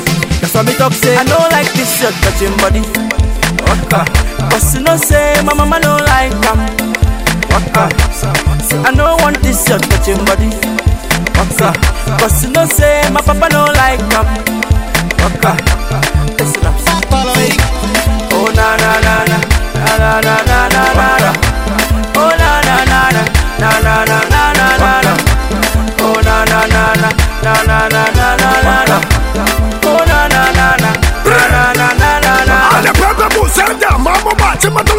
Жанр: Африканская музыка / Поп